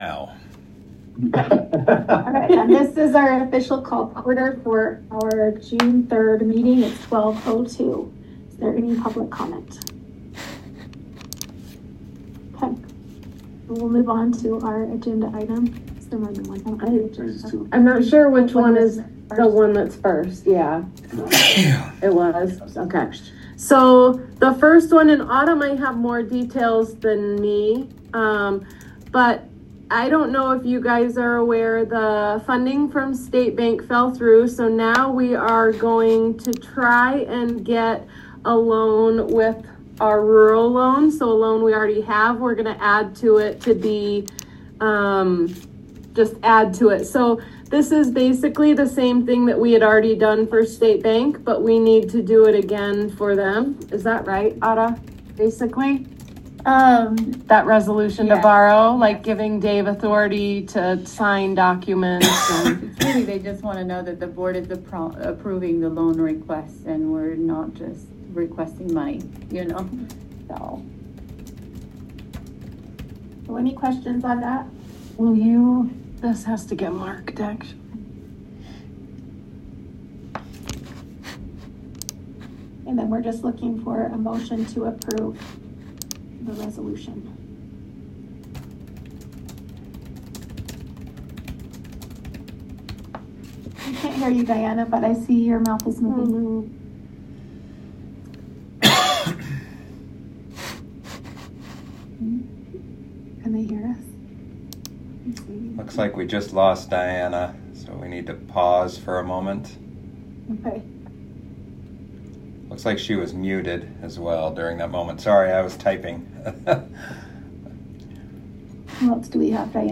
2025-06-03 Board Meeting.m4a